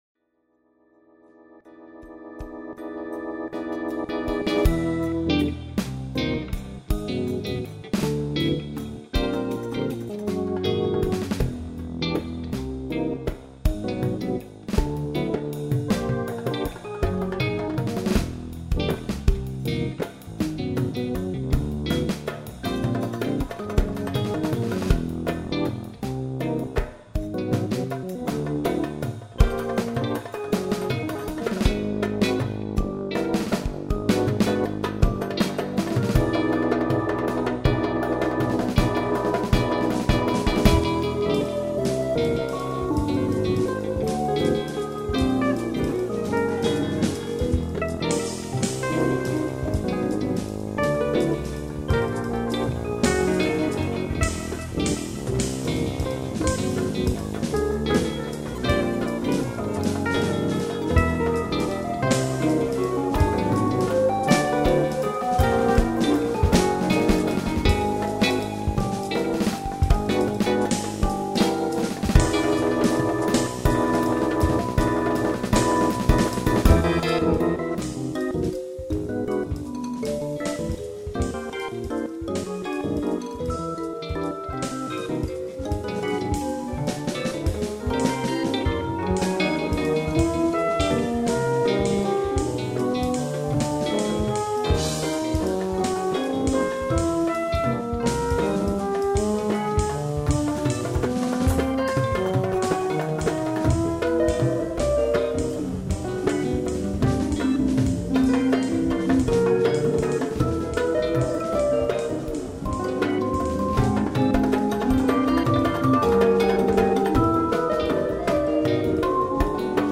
_____a little more than a waltz in a symetrical mode